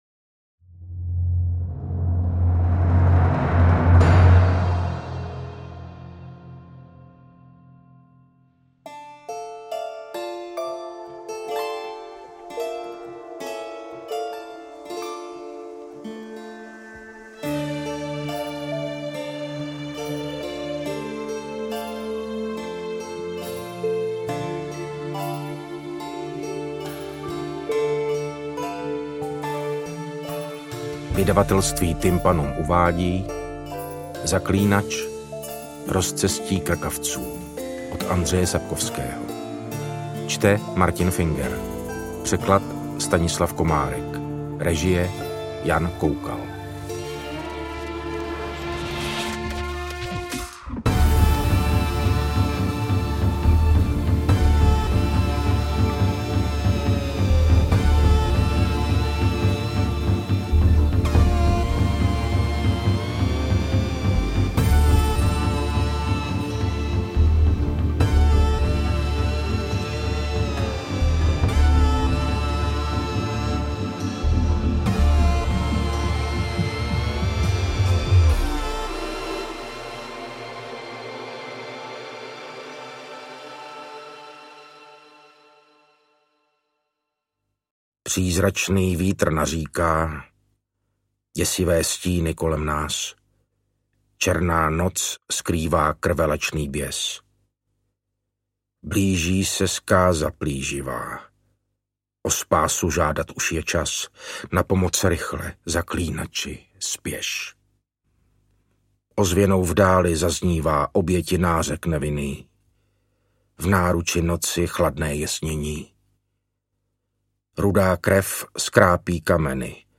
Interpret:  Martin Finger
AudioKniha ke stažení, 26 x mp3, délka 9 hod. 23 min., velikost 516,3 MB, česky